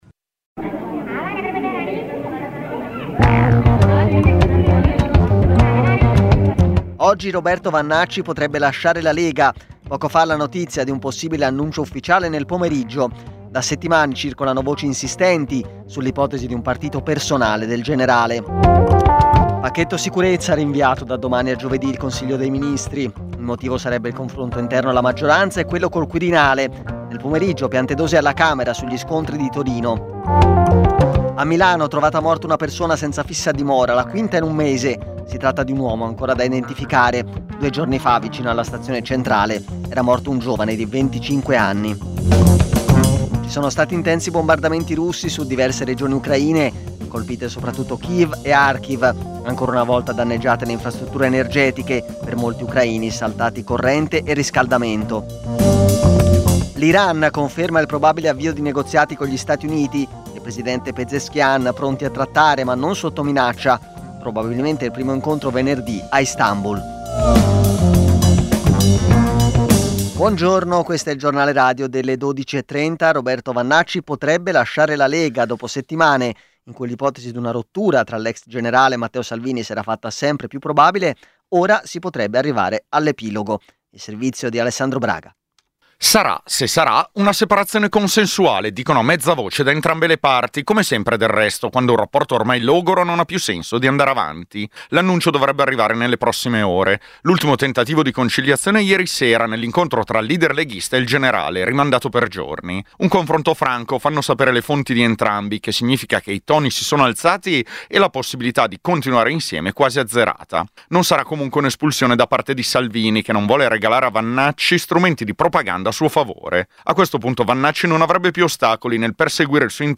Giornale radio Nazionale